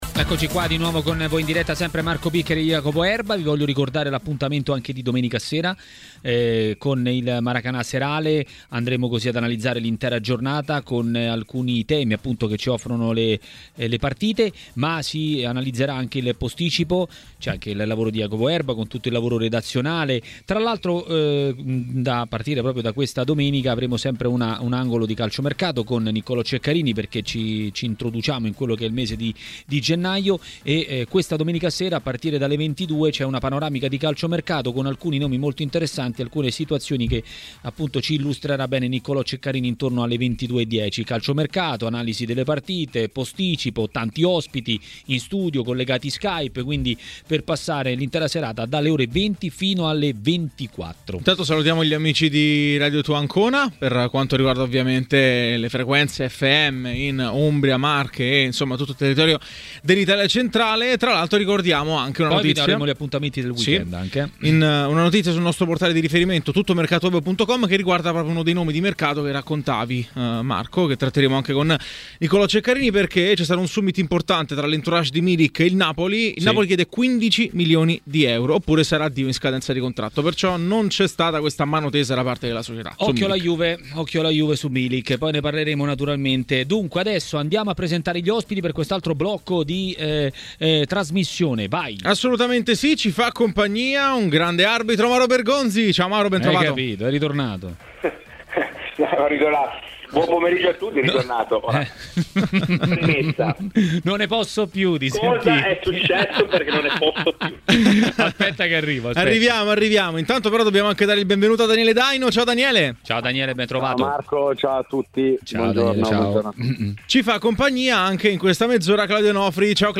ex difensore, ha commentato così i temi del giorno a Maracanà, nel pomeriggio di TMW Radio.